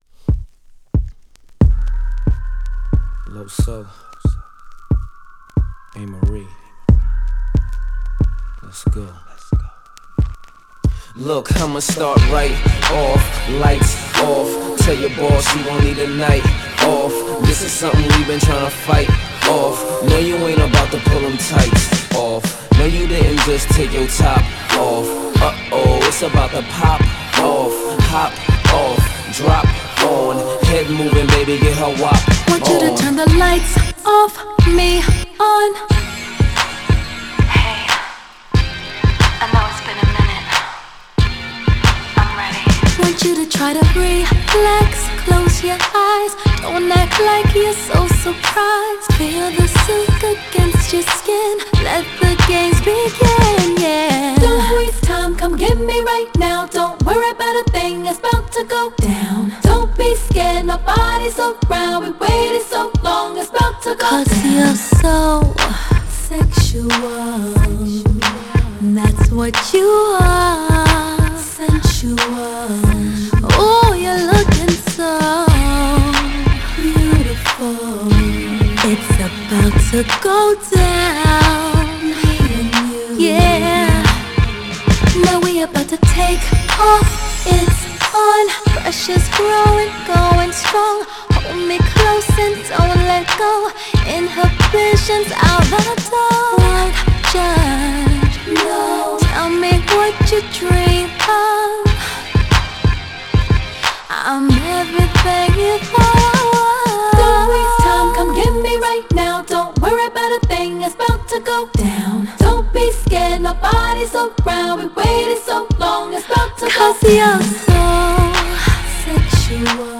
GENRE R&B
BPM 106〜110BPM